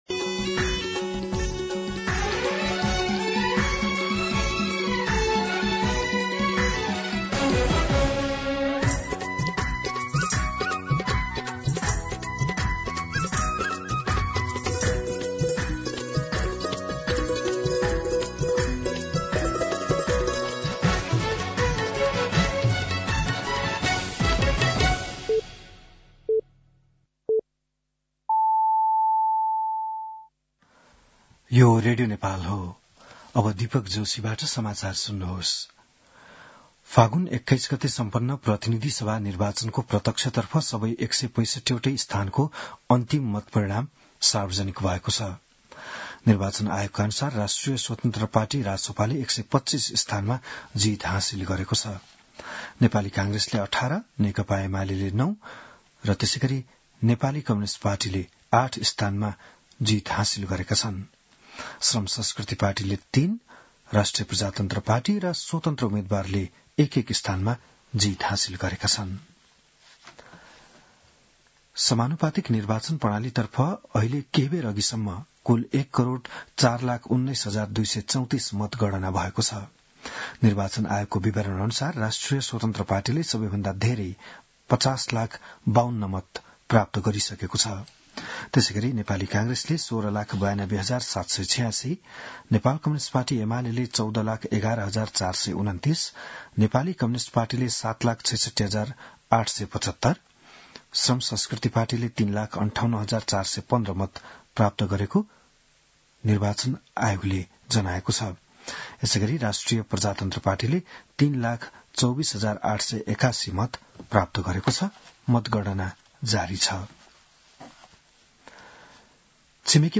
बिहान ११ बजेको नेपाली समाचार : २६ फागुन , २०८२